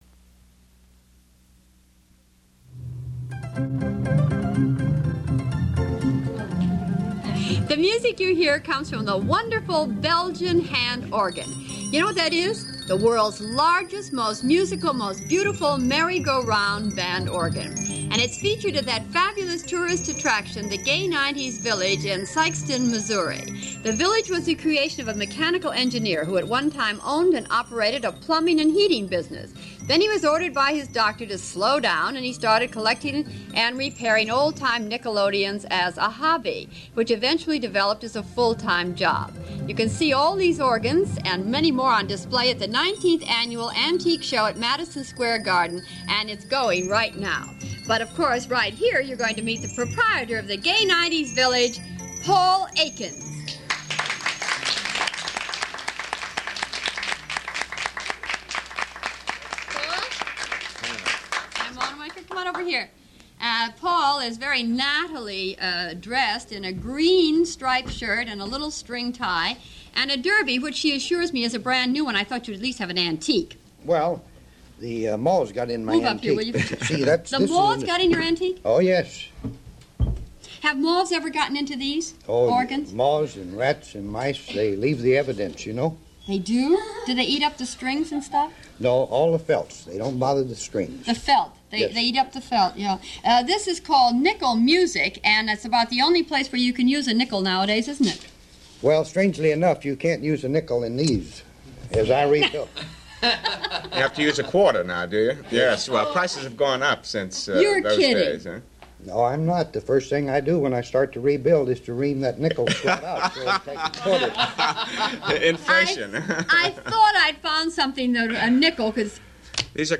These first three short interviews are from 1963.
The second interview is from the program "LEAVE IT TO THE GIRLS"